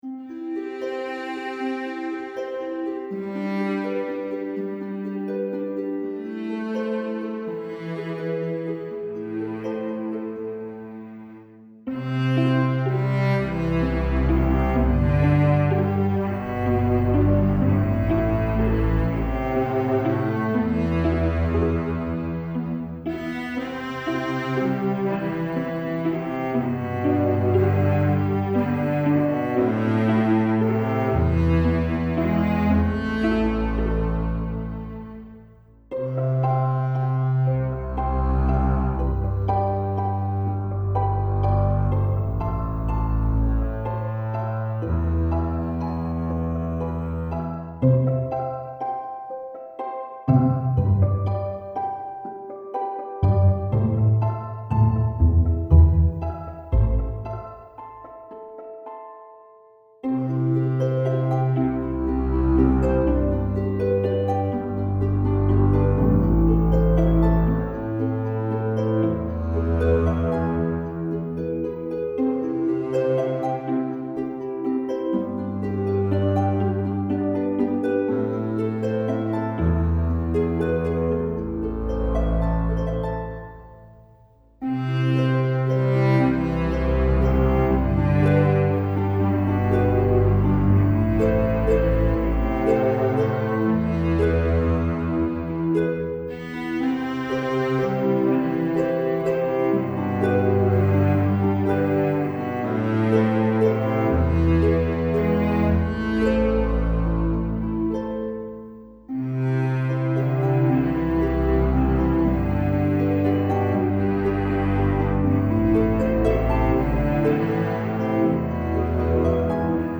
En la mineur, pour flûte de Pan, avec un accompagnement de cordes.
Une très belle pièce, avec quelques variations. Mais ici, la partie de naï n’a pas été enregistrée (le temps se fait de plus en plus rare pour mener mes petites idées à terme…). La partie de naï est donc jouée par une simple traversière, et tout ça étant réalisé en MAO…